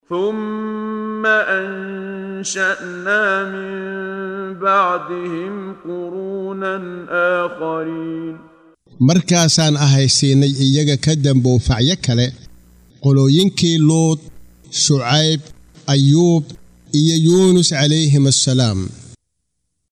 Waa Akhrin Codeed Af Soomaali ah ee Macaanida Suuradda Al-Mu'minuun ( Mu’miniinta ) oo u kala Qaybsan Aayado ahaan ayna la Socoto Akhrinta Qaariga Sheekh Muxammad Siddiiq Al-Manshaawi.